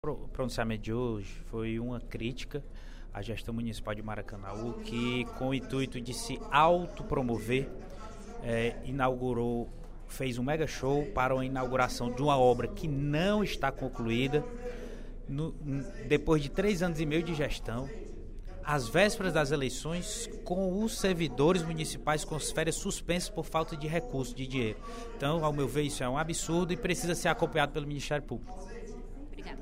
O vice-líder do Governo, deputado Júlio César Filho (PDT) criticou, no primeiro expediente da sessão plenária desta quinta-feira (30/06) da Assembleia Legislativa, o prefeito de Maracanaú, Firmo Camurça, por ter inaugurado uma obra inacabada, empregando dinheiro público em um show de artistas conhecidos (Zezé di Camargo e Luciano).
Dep. Julio Cesar Filho (PDT) Agência de Notícias da ALCE